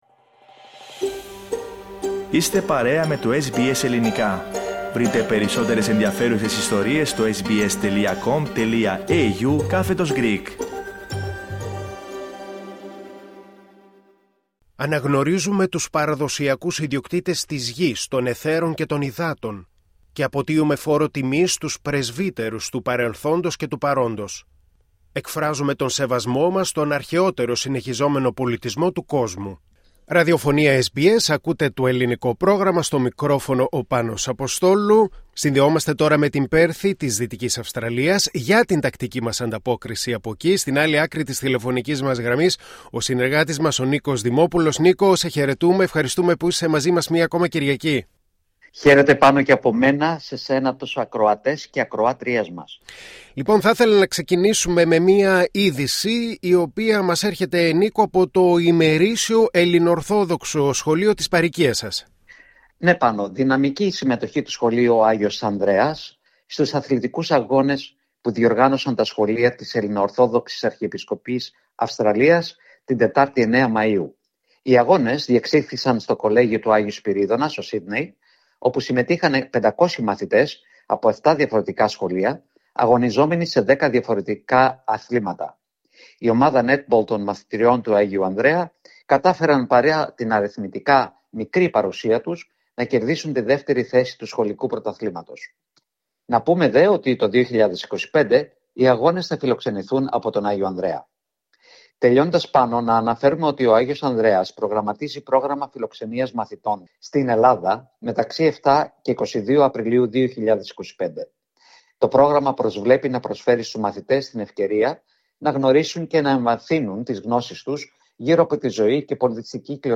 Ακούστε την εβδομαδιαία ανταπόκριση από την Δυτική Αυστραλία